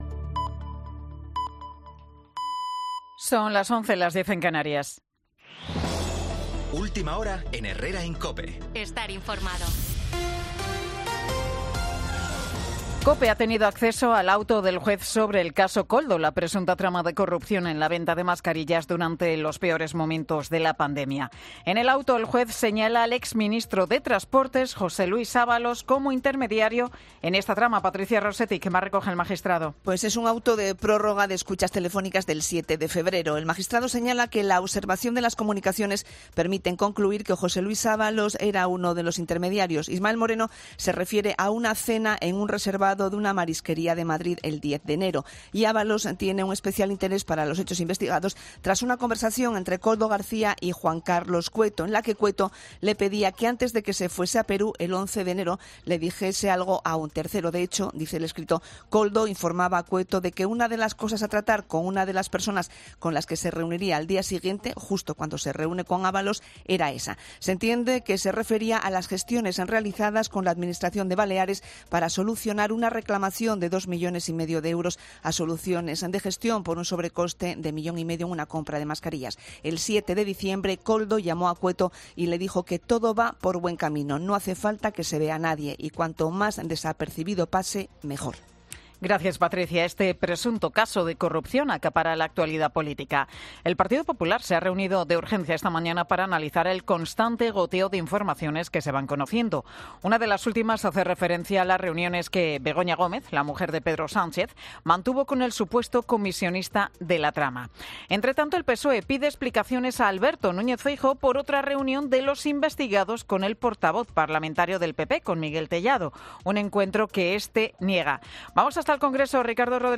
Boletín de Noticias de COPE del 29 de febrero del 2024 a las 11 horas